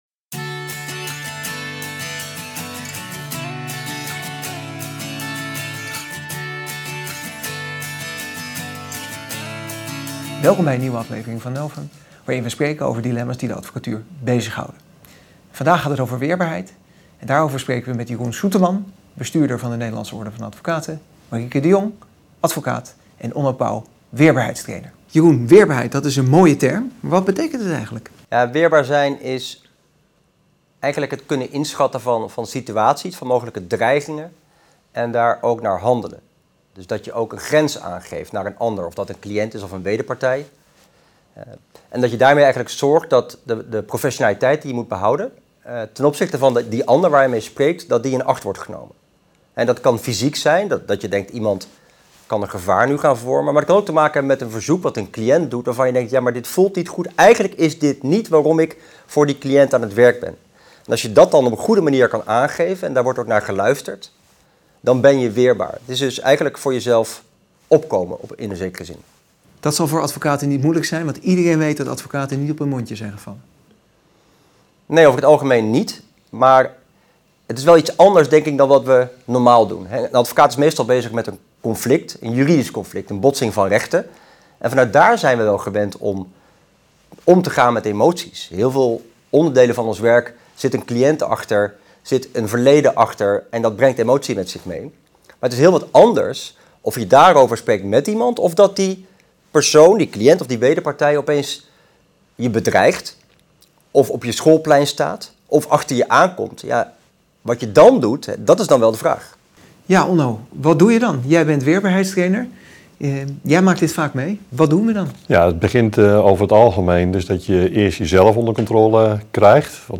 Gasten uit en rondom de advocatuur geven hun visie op het vak en hun ontwikkeling.
De NOvA organiseert onder de naam NOvUM digitale debatten en interviews over de actualiteit en de praktijkvoering van advocaten.